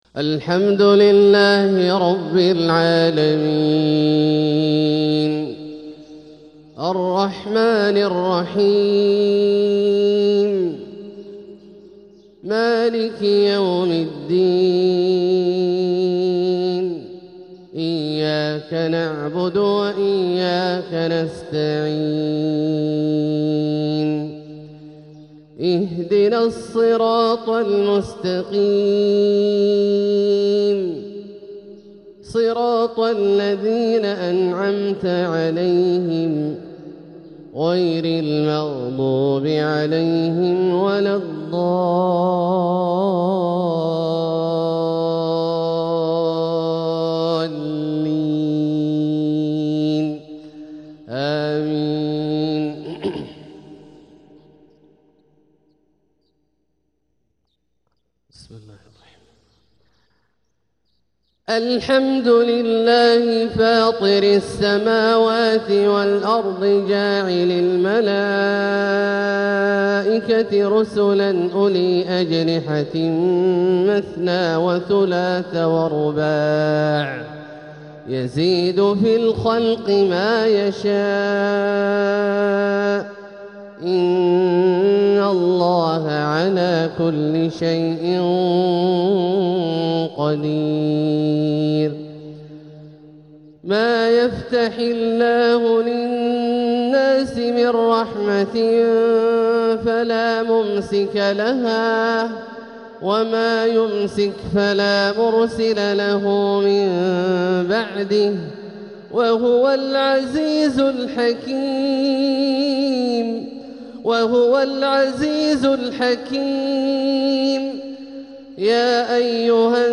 تلاوة رائعة بترنم مميز لـ فواتح سورة فاطر | فجر 6-6-1447هـ > ١٤٤٧هـ > الفروض - تلاوات عبدالله الجهني